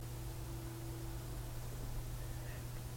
Hillburn, NY #2 December 2, 2011 evps
At the beginning of the investigation several digital audio recorders were placed in rooms that had a history of unexplained events.
This clip is interesting in that both investigators were running recorders and we got the same evp on both.
The first is unenhanced.